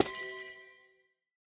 Minecraft Version Minecraft Version snapshot Latest Release | Latest Snapshot snapshot / assets / minecraft / sounds / block / amethyst_cluster / break4.ogg Compare With Compare With Latest Release | Latest Snapshot
break4.ogg